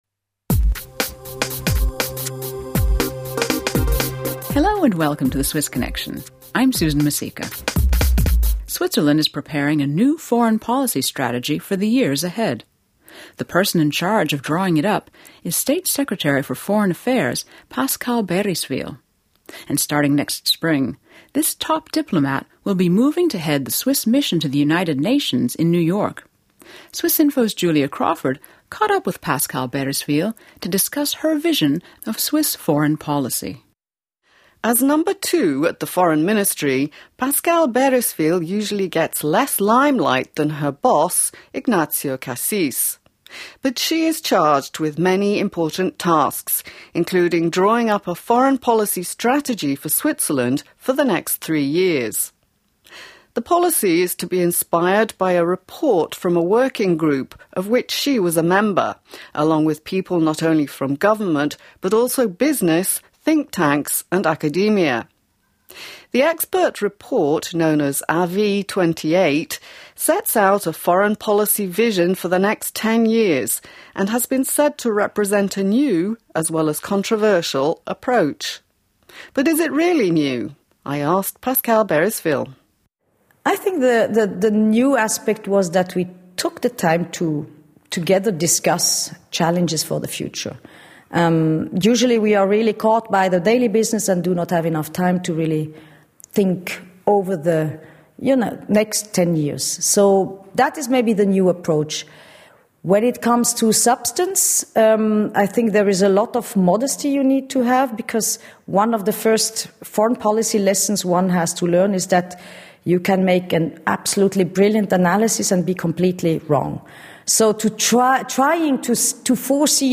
Podcast of interview with Pascale Baeriswyl